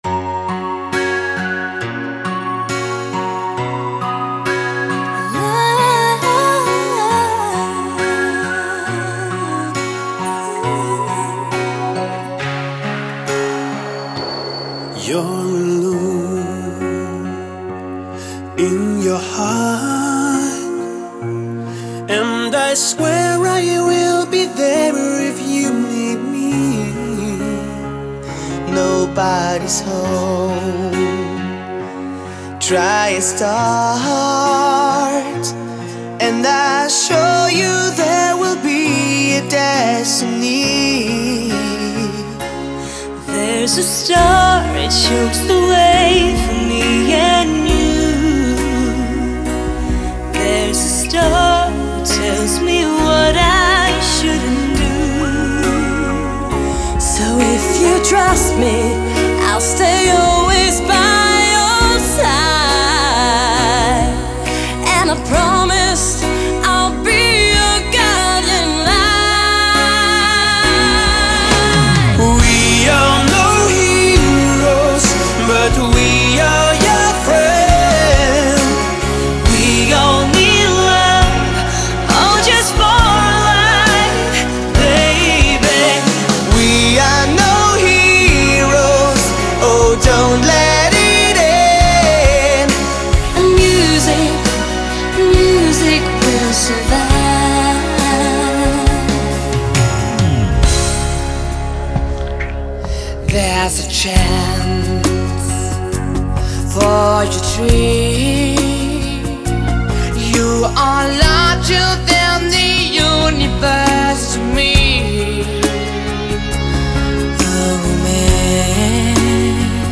那声音如同他们的气质、身体一般，有一点点虚幻、有一点点深邃，更有一点点感动在里头，是一种安静，像极了琥珀